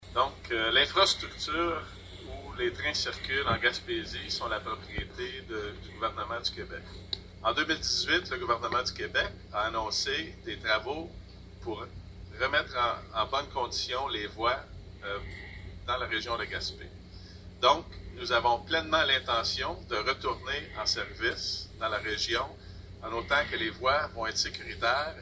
VIA Rail tenait le 28 mai dernier son Assemblée annuelle publique à Montréal.